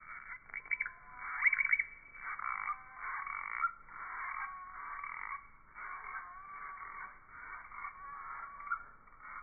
English: Saccopteryx bilineata scenting display call (slow-mo) at the Tiputini Biodiversity Research Center, Ecuador
Greater sac-winged bat
Saccopteryx_bilineata_scenting_call.wav